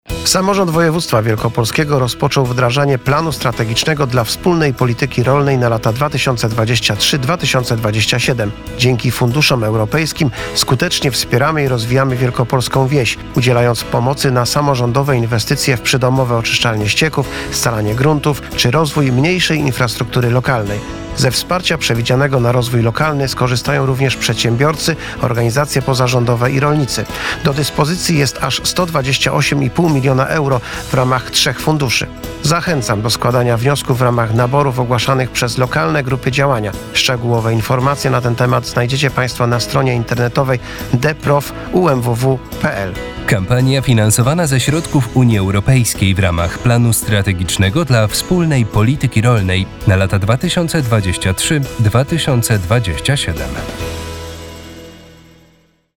Prezentujemy spoty radiowe emitowane w związku z realizacją obowiązków w zakresie informacji i promocji Planu Strategicznego dla Wspólnej Polityki Rolnej na lata 2023-2027 przez Samorząd Województwa Wielkopolskiego.
marszalek_polityka_rolna.mp3